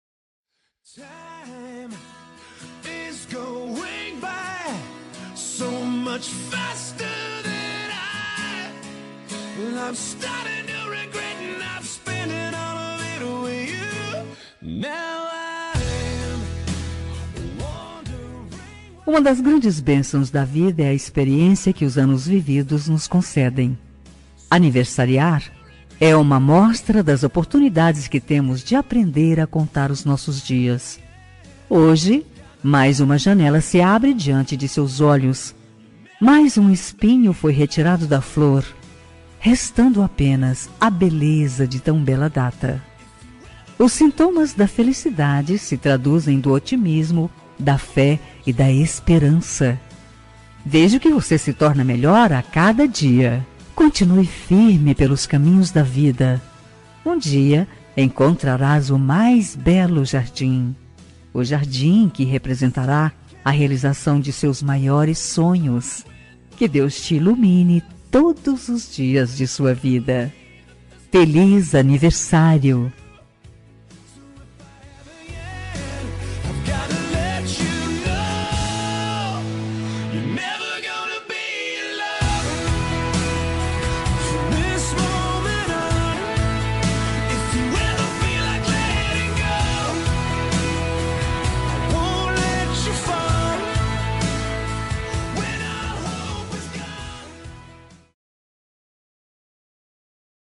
Telemensagem de Aniversário de Pessoa Especial – Voz Feminina – Cód: 9096